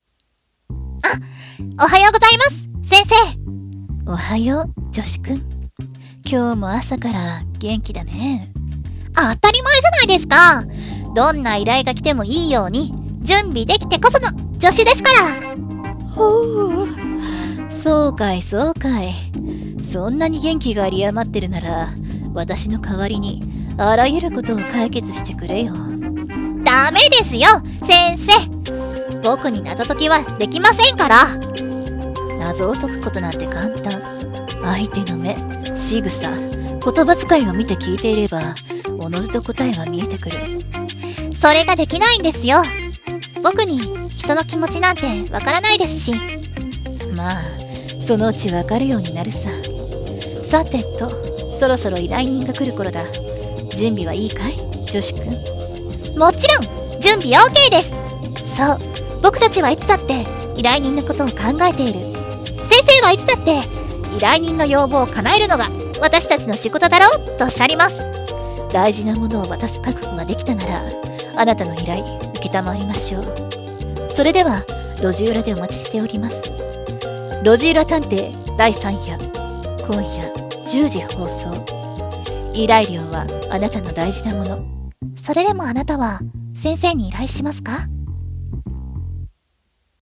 ドラマ予告風声劇台本『路地裏探偵⒊』